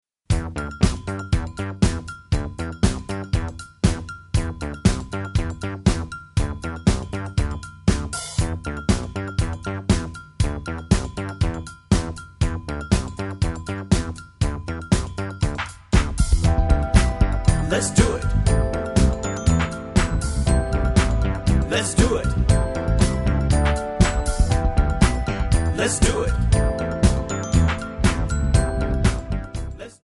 Backing track Karaoke
Pop, Disco, 1980s